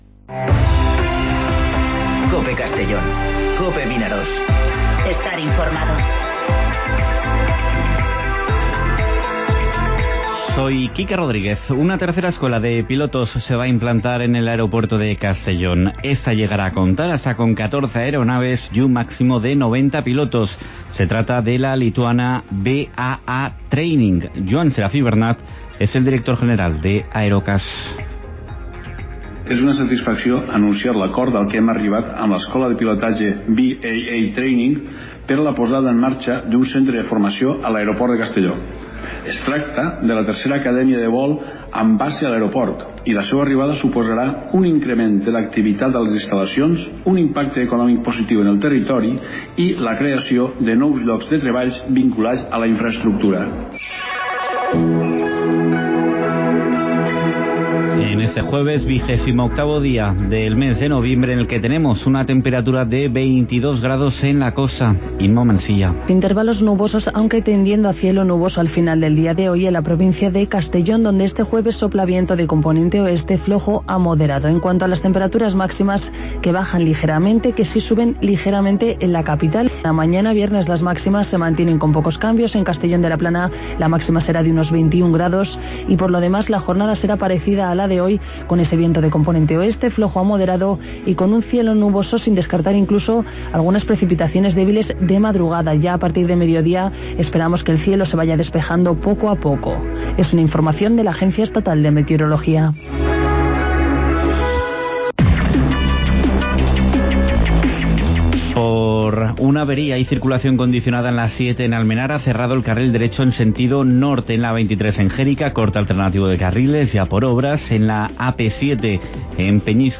Informativo Mediodía COPE en Castellón (28/11/2019)